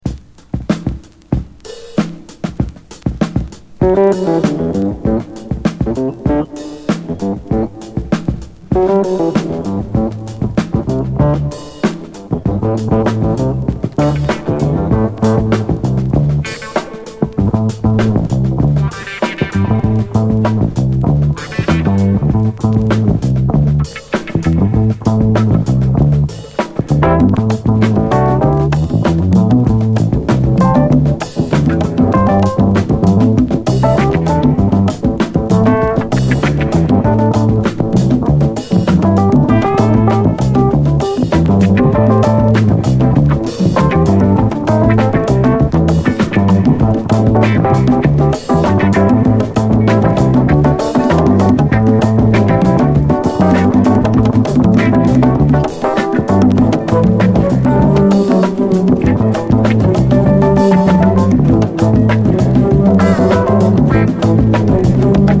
JAZZ FUNK / SOUL JAZZ, JAZZ
定番ドラム・ブレイクで始まる